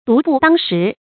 独步当时 dú bú dāng shí
独步当时发音